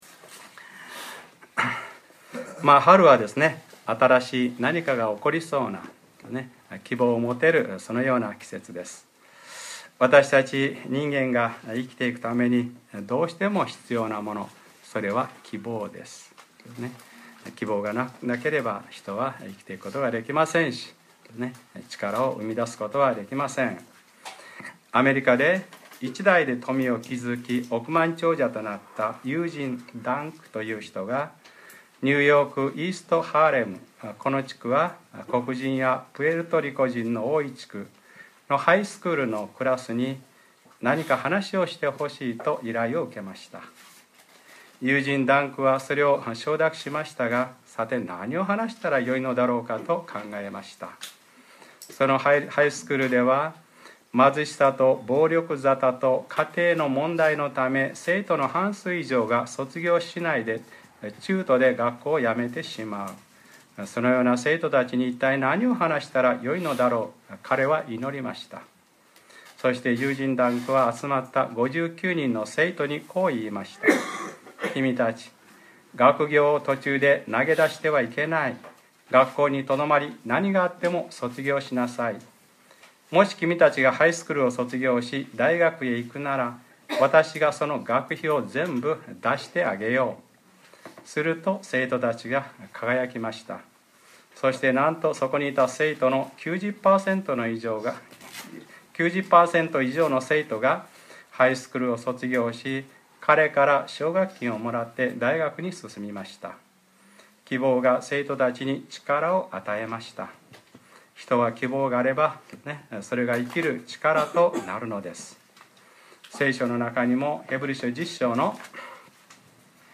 2014年 3月23日（日）礼拝説教『あらゆる国の人々を弟子としなさい』